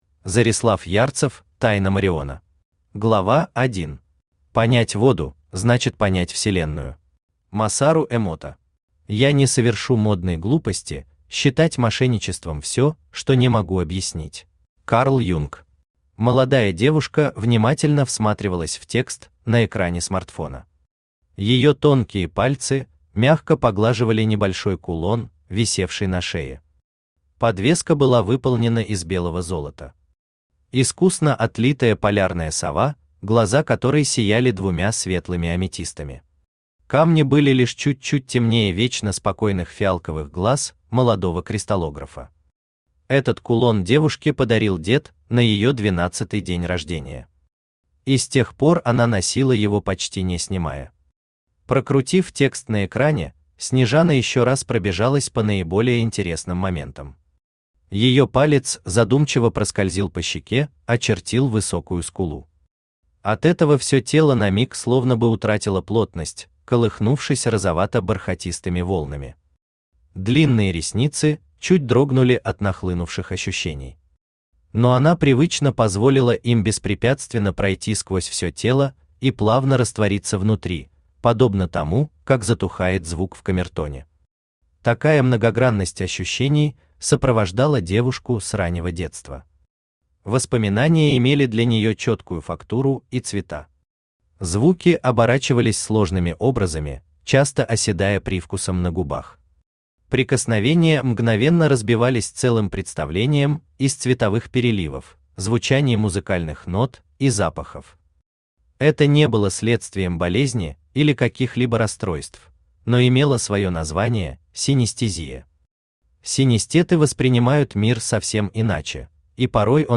Aудиокнига Тайна мориона Автор Зорислав Ярцев Читает аудиокнигу Авточтец ЛитРес.